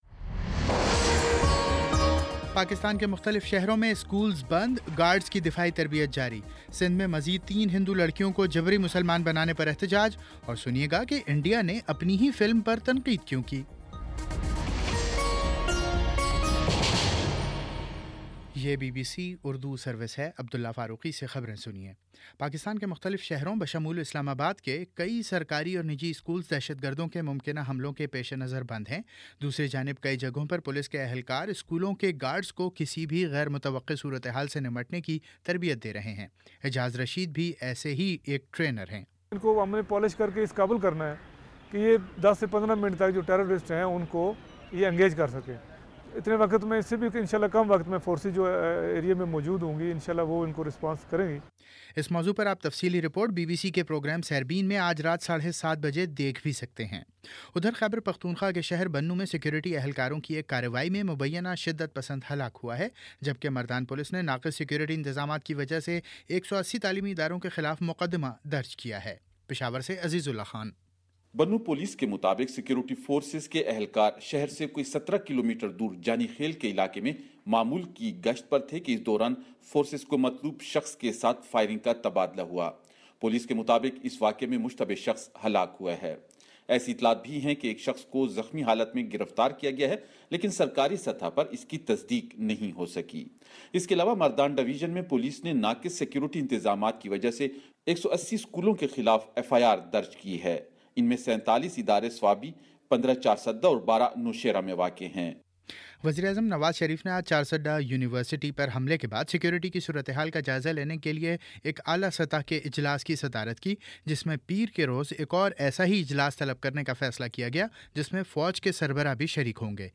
جنوری 29 : شام چھ بجے کا نیوز بُلیٹن